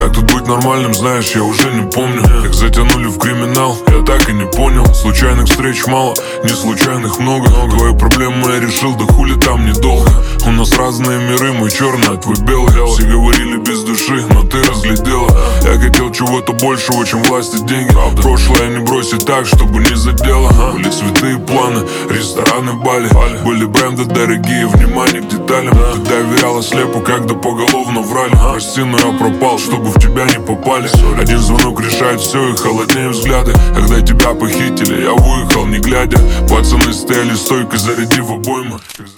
Русские рингтоны
поп